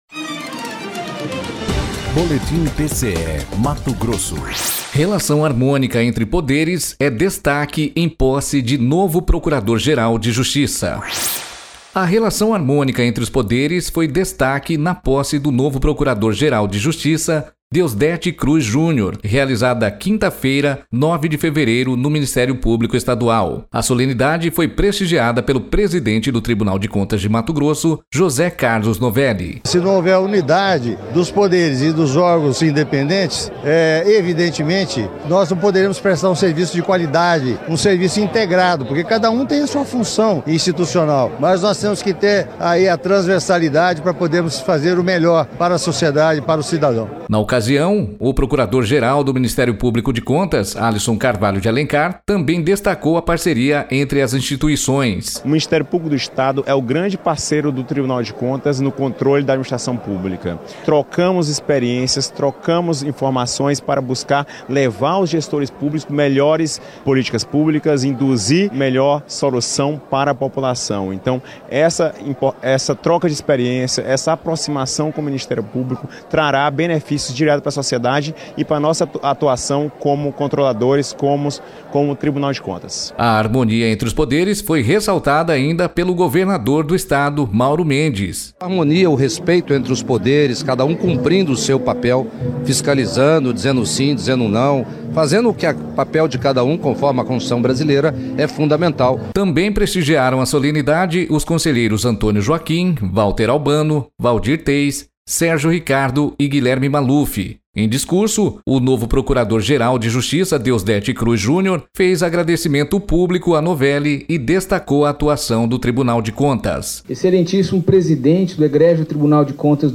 Sonora: José Carlos Novelli – conselheiro presidente do TCE-MT
Sonora: Alisson Carvalho de Alencar - procurador-geral do MPC-MT
Sonora: Mauro Mendes – governador de MT
Sonora: Deosdete Cruz Junior - procurador-geral de Justiça de MT